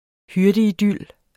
Udtale [ ˈhyɐ̯dəiˌdylˀ ]